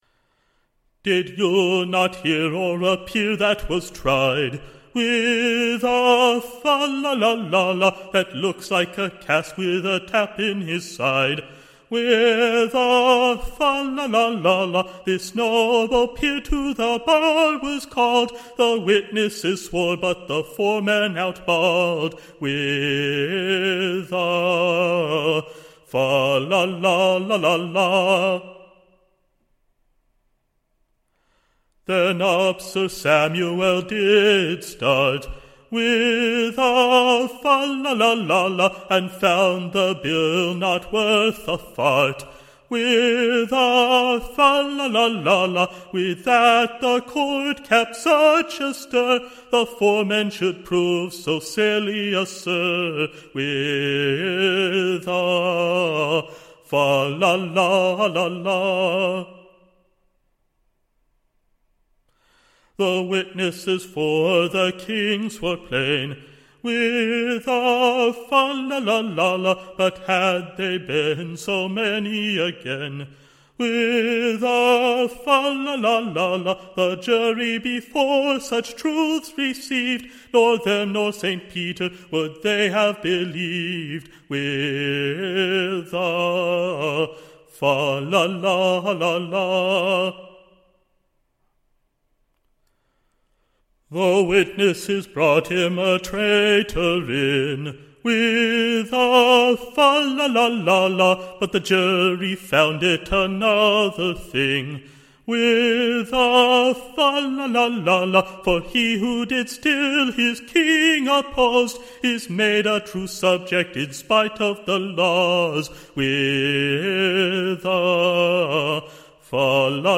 / An Excellent New SONG.